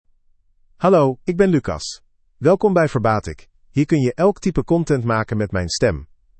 Lucas — Male Dutch AI voice
Lucas is a male AI voice for Dutch (Netherlands).
Voice sample
Male
Lucas delivers clear pronunciation with authentic Netherlands Dutch intonation, making your content sound professionally produced.